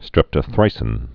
(strĕptə-thrīsĭn, -thrĭsĭn)